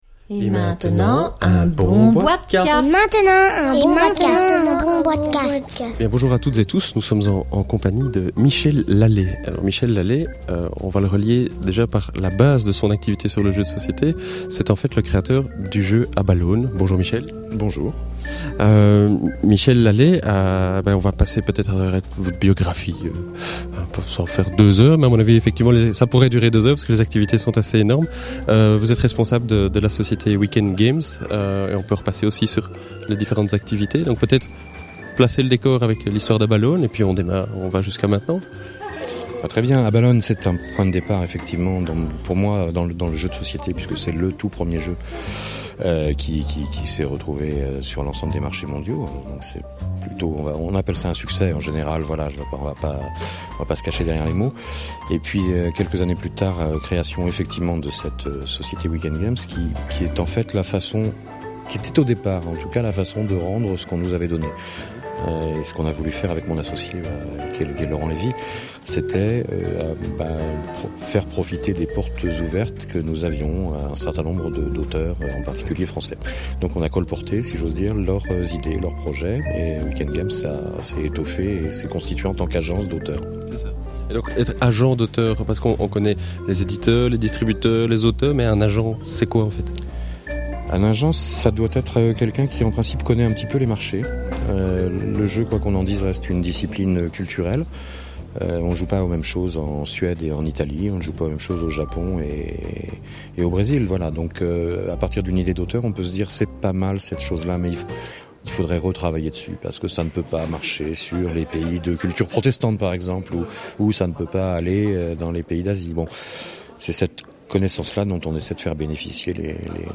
( enregistré lors du Nuremberg SpielenwarenMesse 2009 )